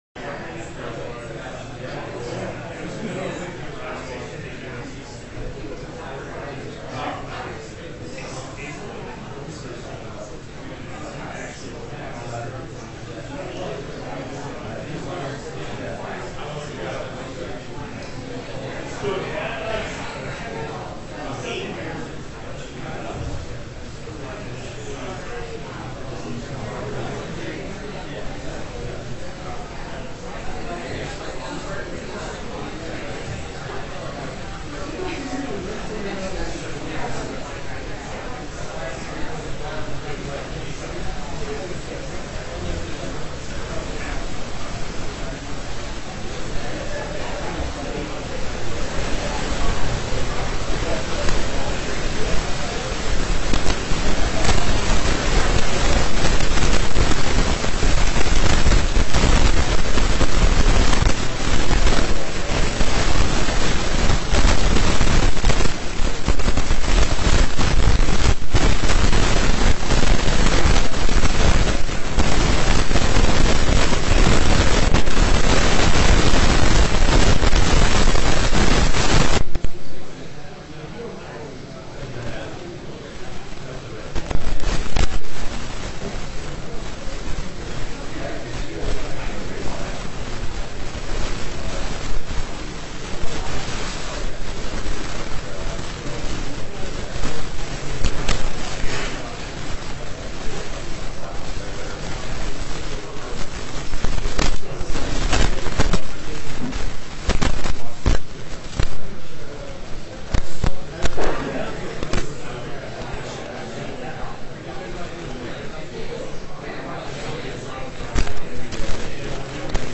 Oral Arguments